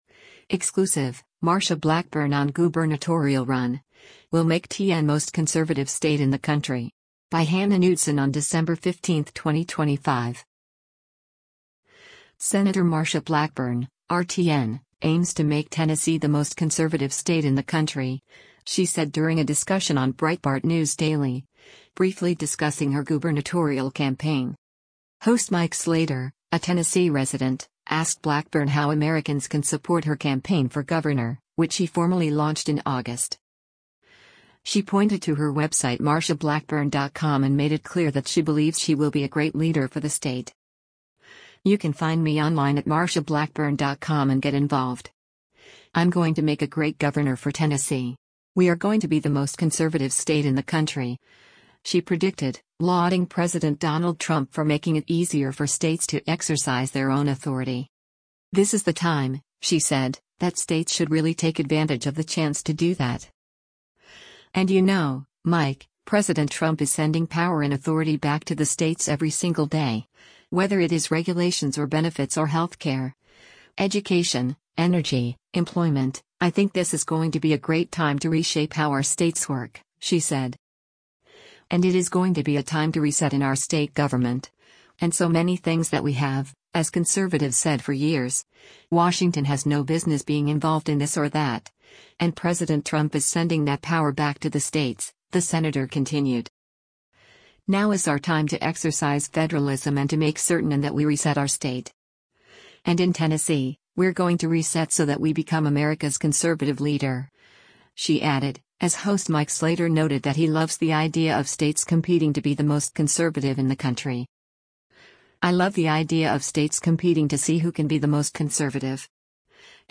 Sen. Marsha Blackburn (R-TN) aims to make Tennessee the “most conservative state in the country,” she said during a discussion on Breitbart News Daily, briefly discussing her gubernatorial campaign.